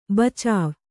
♪ bacāv